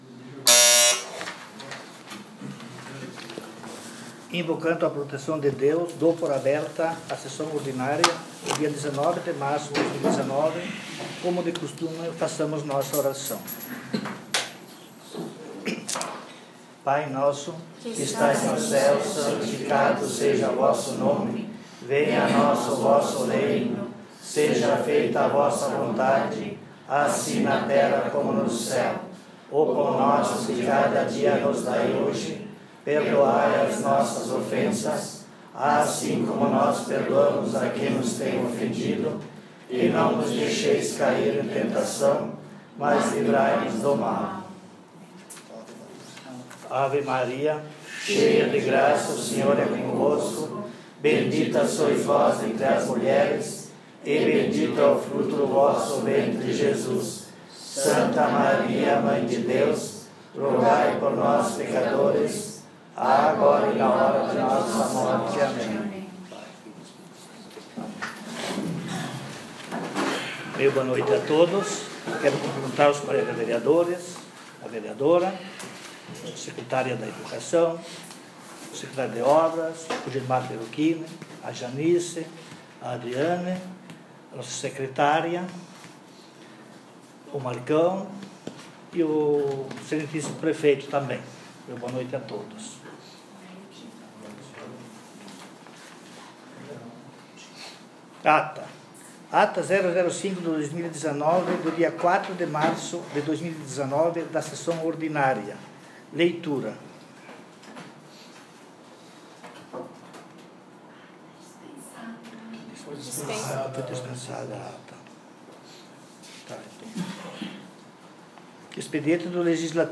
Sessão Ordinária dia 19/03